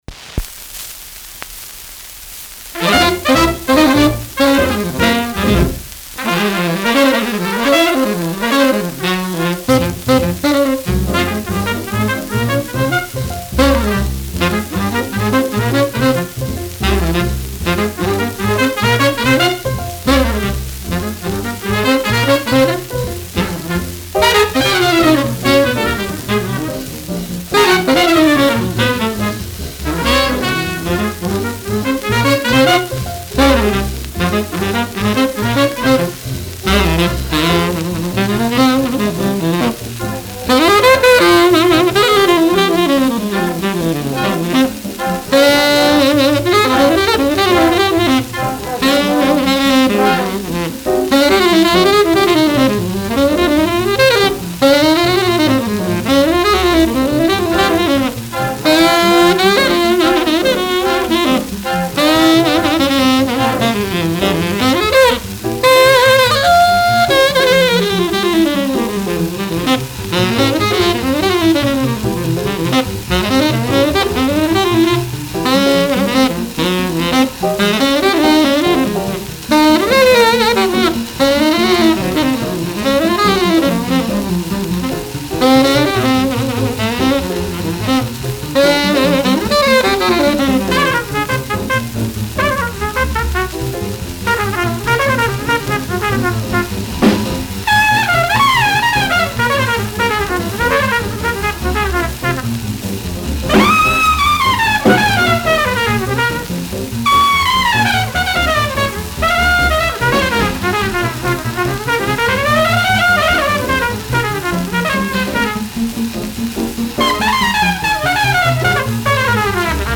Recorded January 9, 1945 in New York